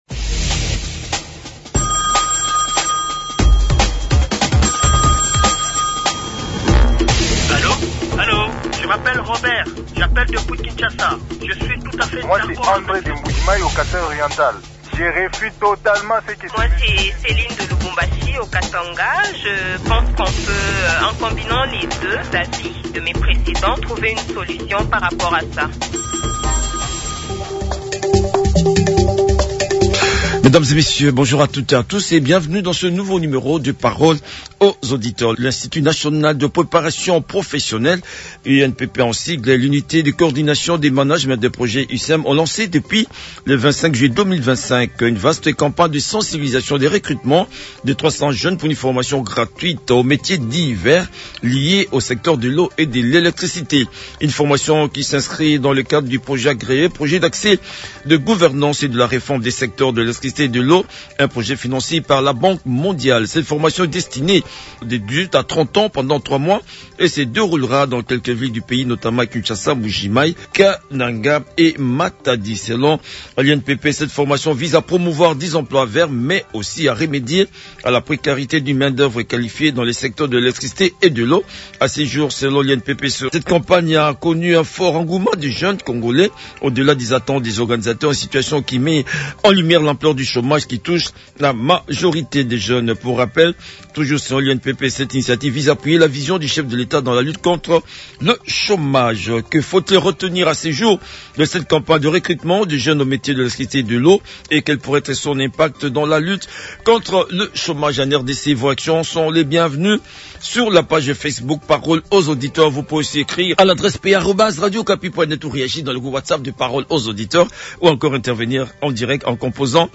Les auditeurs ont échangé avec